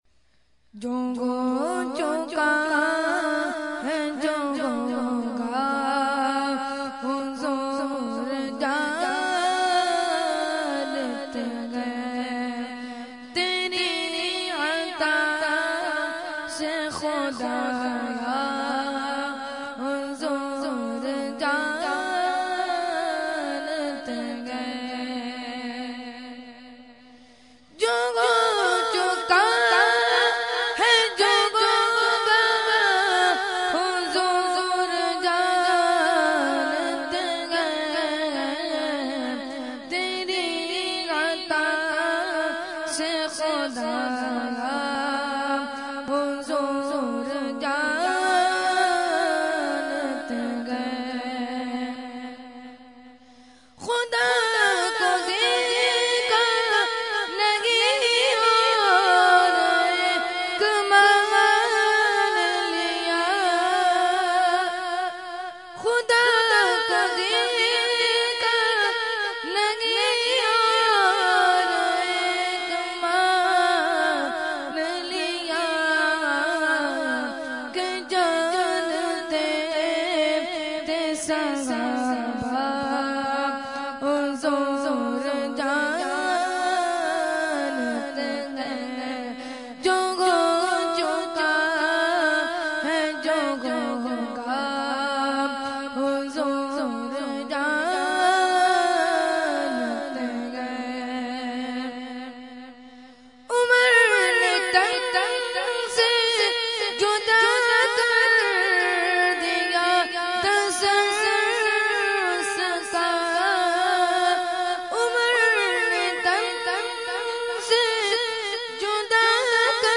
Category : Naat | Language : UrduEvent : 11veen Sharif Lali Qila Lawn 2015